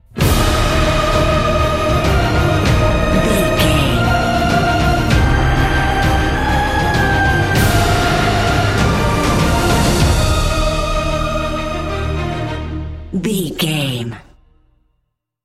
Ionian/Major
E♭
cinematic
energetic
brass
cello
drums
strings
trumpet